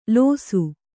♪ lōsu